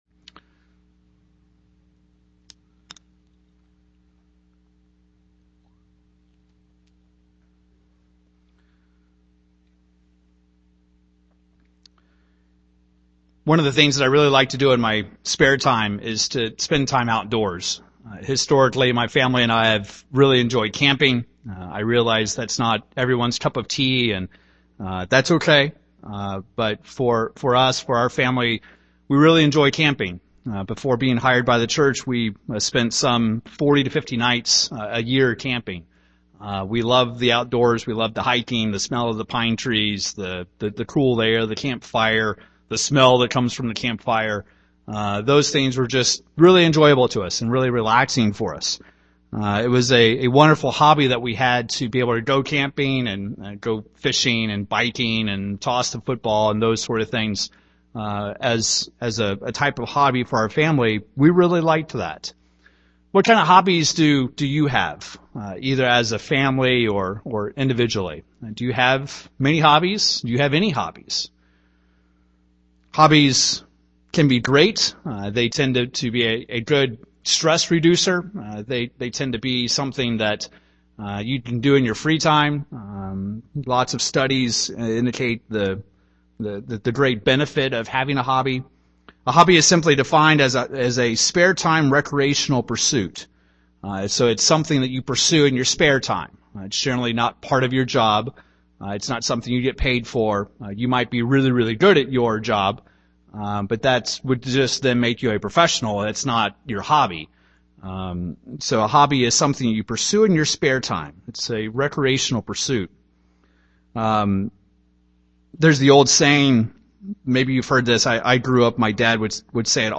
In this sermon, we discuss the importance of being resolute in not letting life’s challenges or persecutions make God a hobby in your life.